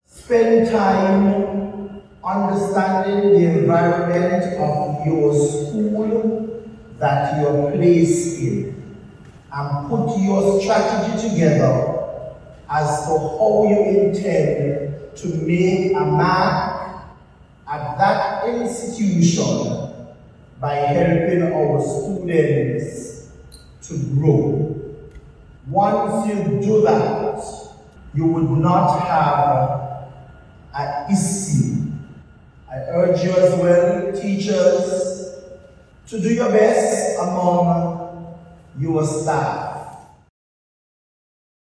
Deputy Prime Minister, Dr. Geoffrey Hanley made the remarks at an In-Service Training for new Teachers on Tuesday Sep. 24th, in which he officially declared the initiative open.
Deputy Prime Minister of Education, Hon. Dr. Geoffrey Hanley.